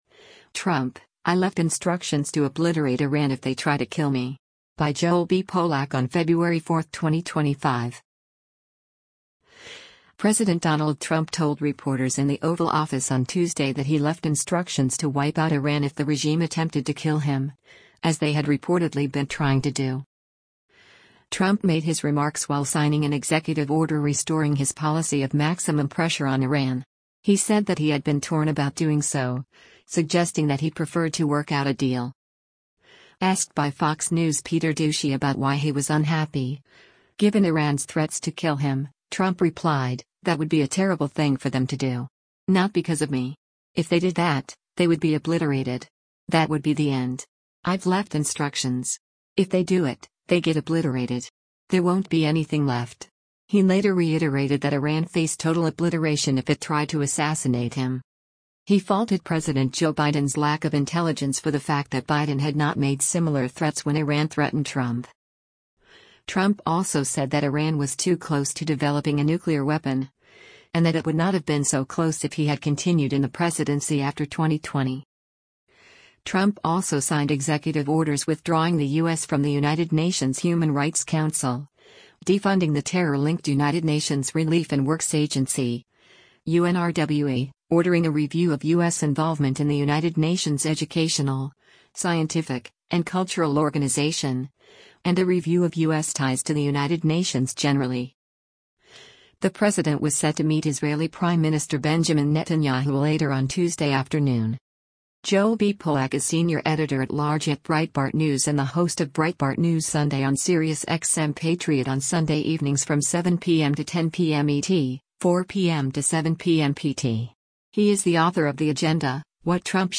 President Donald Trump speaks to reporters as he signs executive orders in the Oval Office
President Donald Trump told reporters in the Oval Office on Tuesday that he “left instructions” to wipe out Iran if the regime attempted to kill him, as they had reportedly been trying to do.